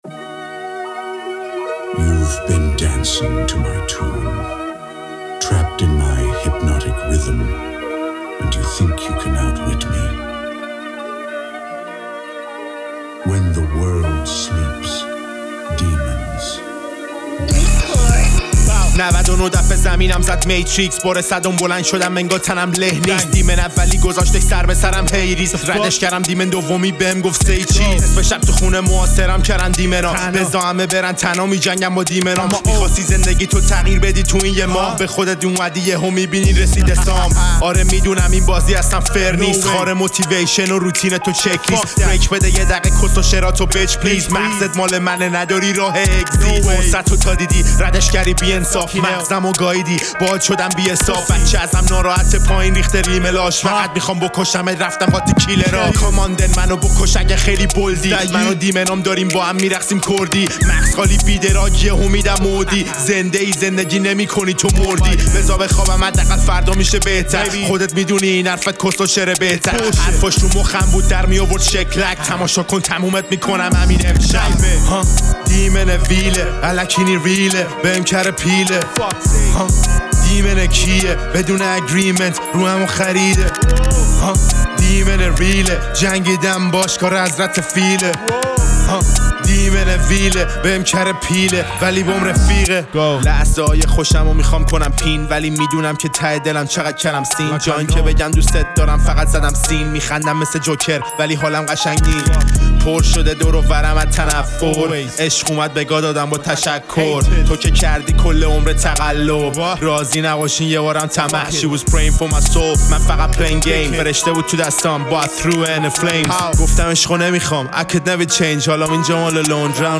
با فلو سریع و ریتم جذاب
هیپ‌هاپ و فری استایل
یک آهنگ رپ پرانرژی و شنیدنی